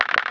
bot_move.wav